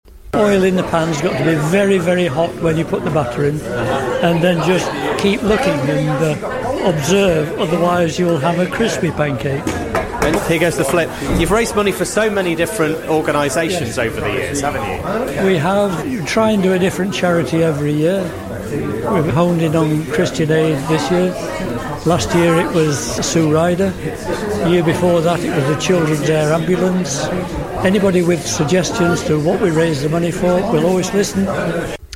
Last Saturday, the long running pancake morning took place at Ketton Methodist Church, which this year raised funds for Christian Aid.